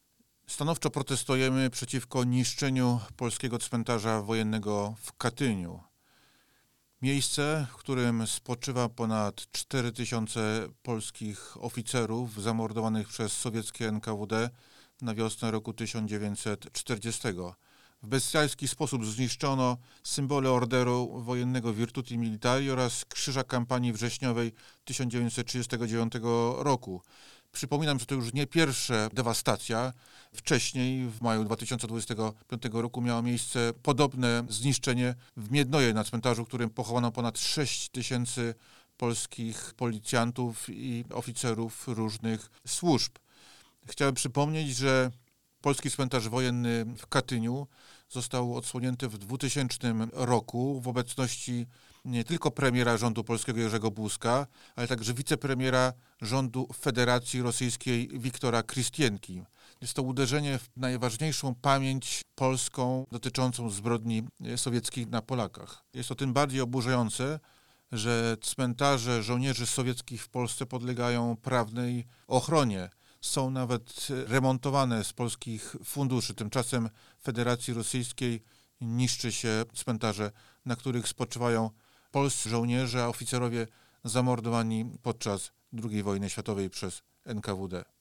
zastepcaprezesaIPNdrMateuszSzpytma-OswiadczenieInstytutuPamieciNarodowejwsprawie.mp3